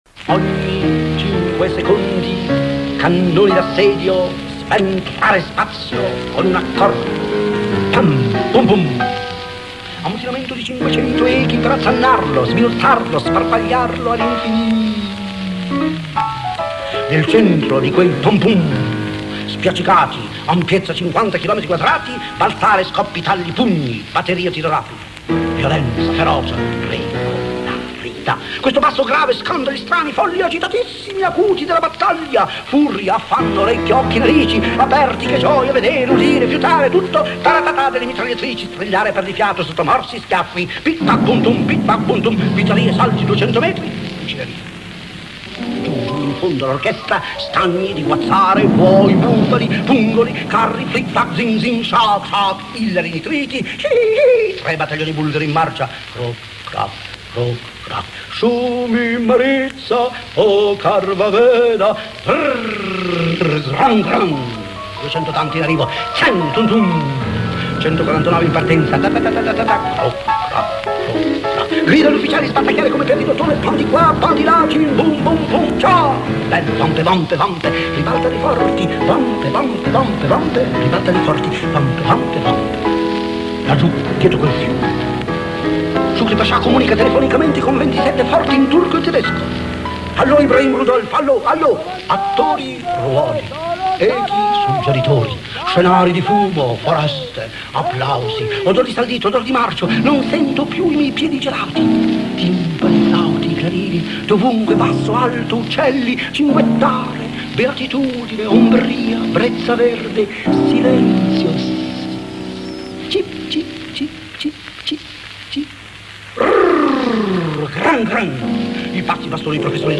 Zang Tumb Tumb F.T.Marinetti esempio di poesia futurista.mp4